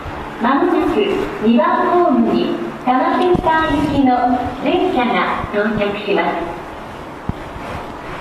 TM06 -- 接近放送 0:08 -- 多摩センター方面。東海道型と同じ声です。